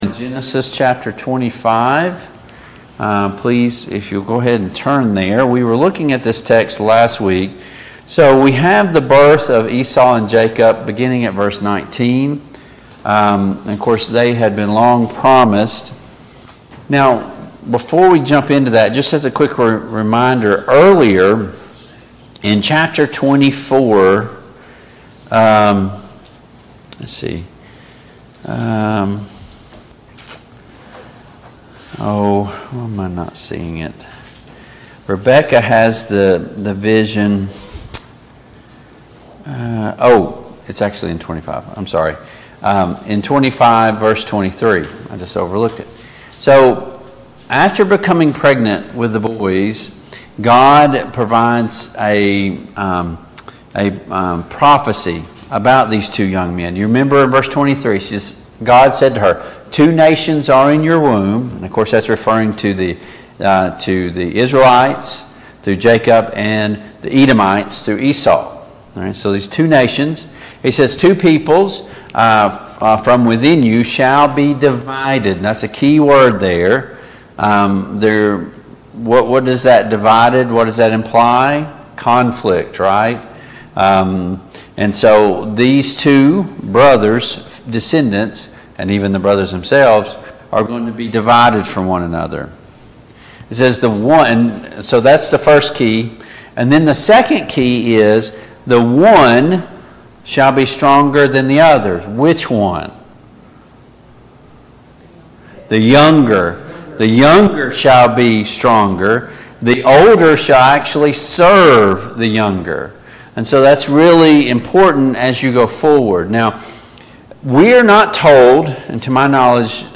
Study of Genesis Passage: Genesis 25, Genesis 26 Service Type: Family Bible Hour Topics